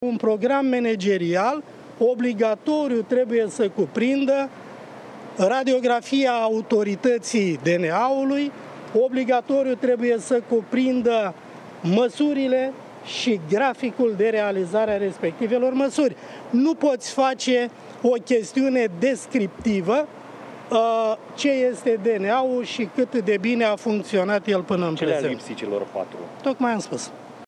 Programele manageriale nu au fost suficient de bune, lasă de înțeles ministrul Justiţiei, întrebat, luni dimineață de jurnaliști de ce a respins toți procurorii înscriși în cursa pentru șefia Direcției Naționale Anticorupție.